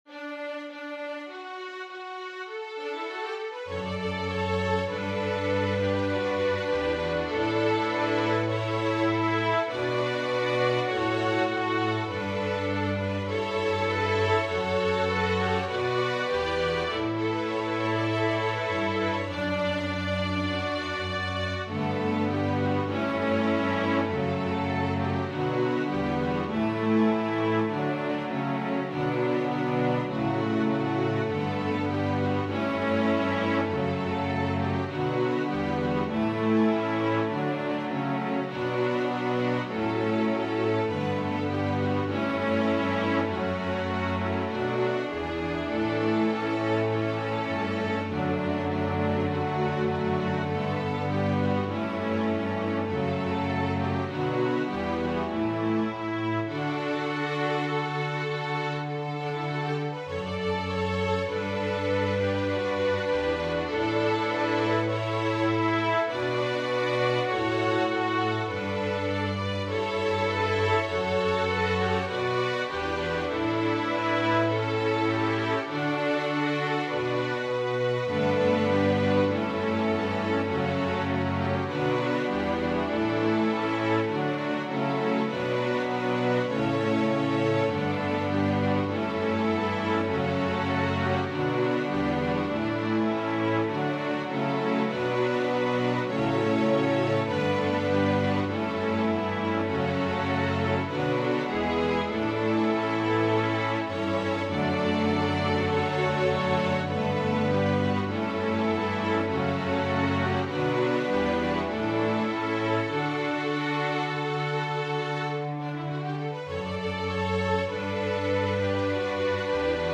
This is an old hymn (over 150 years old) by Robert Williams that has been arranged by the one and only Mack Wilberg.
Voicing/Instrumentation: Organ/Organ Accompaniment We also have other 6 arrangements of " Hail the Day That Sees Him Rise ".